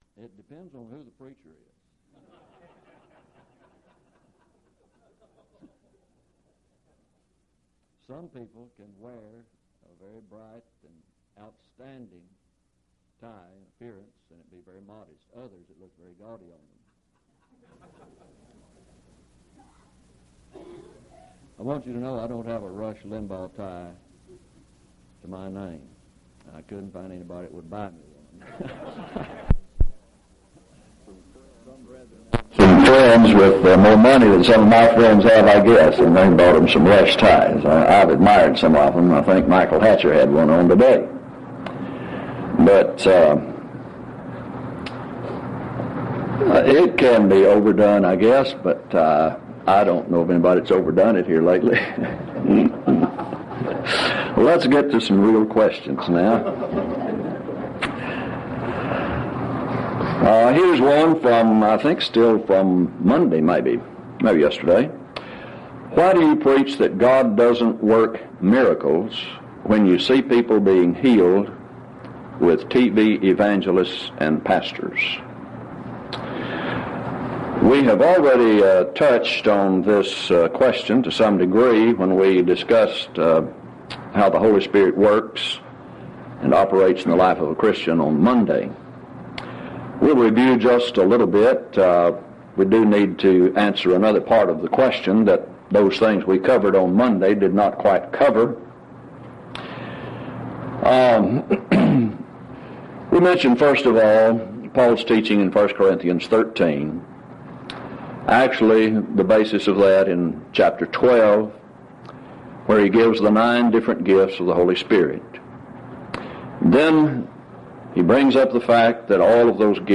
Title: Open Forum Speaker(s): Various
Event: 2nd Annual Lubbock Lectures Theme/Title: Looking Unto Jesus -- The Author & Finisher of Our Faith